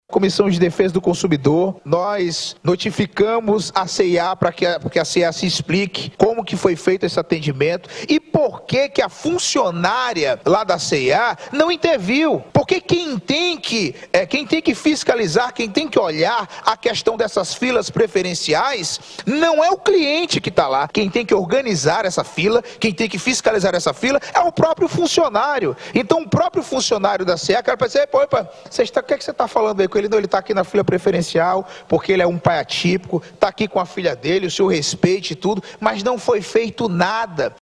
De acordo com o Presidente da Comissão de Defesa do Consumidor da Aleam, Deputado Estadual, Mario Cesar Filho, o direito a fila preferencial foi negado pelos próprios funcionários da loja. Ouça o que diz o Deputado:
Sonora-Mario-Cesar-Filho.mp3